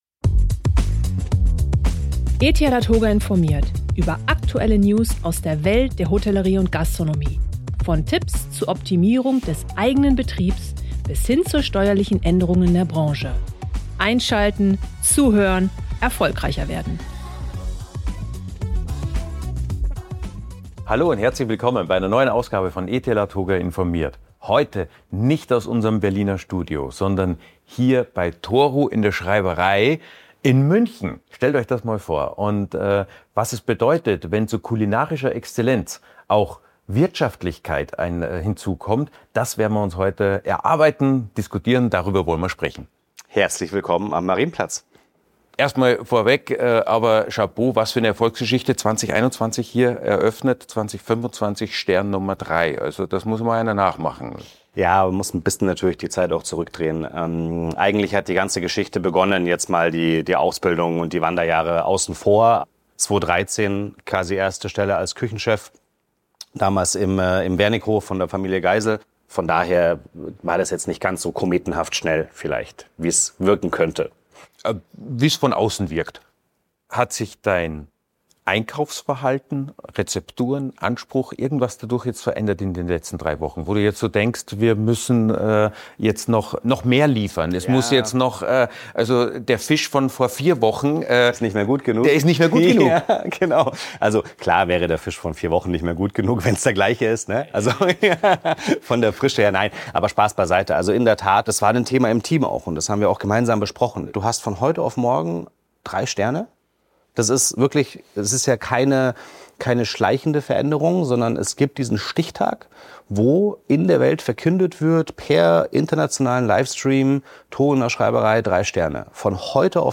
#120 | Wenn aus Sternen Taler werden – Sternekoch im Interview ~ ETL ADHOGA informiert!